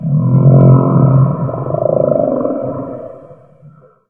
gunship_moan.ogg